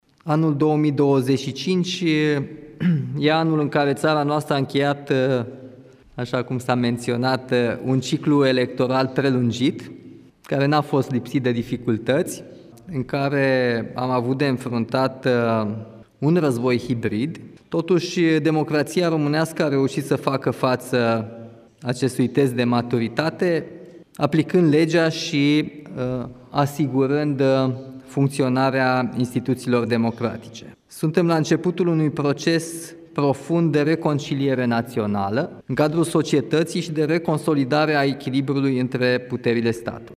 România este la începutul unui proces de reconciliere națională, transmite președintele Nicușor Dan. Declarația a fost făcută în cadrul întâlnirii anuale cu șefii misiunilor diplomatice din România.